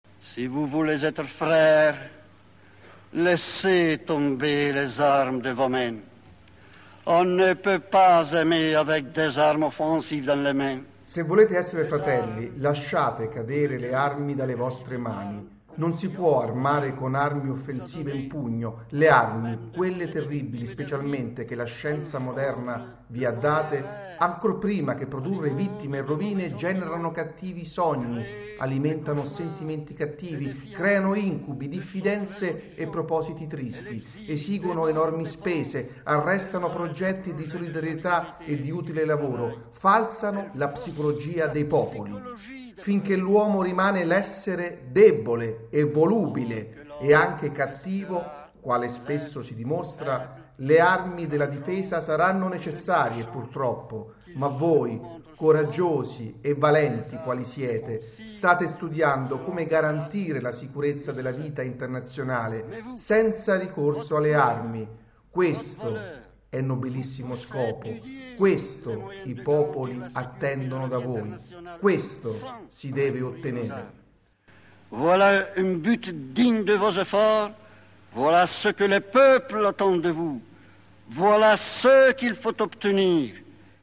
This occasion was the historical visit, on October 4, 1965, to the United Nations: